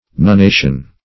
Search Result for " nunnation" : The Collaborative International Dictionary of English v.0.48: Nunnation \Nun*na"tion\, n. [From nun, the Arabic name of the letter n: cf. NL. nunnatio, F. nunnation.]
nunnation.mp3